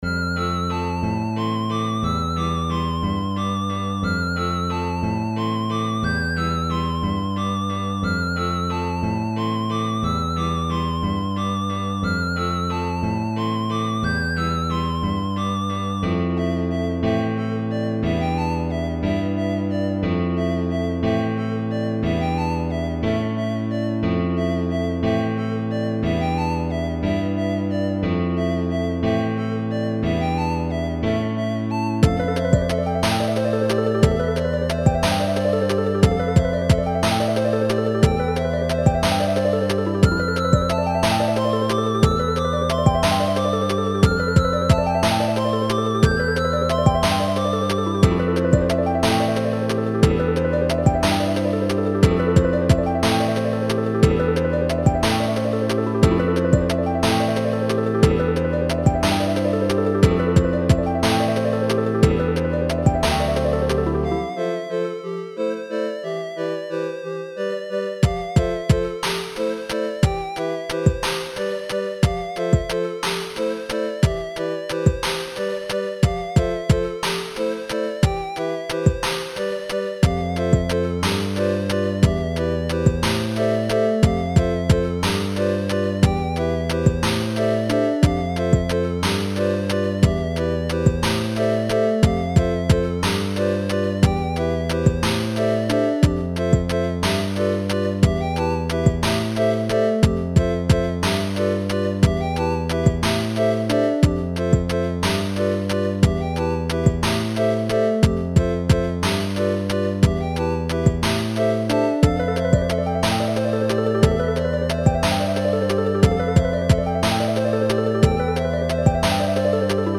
Should loop well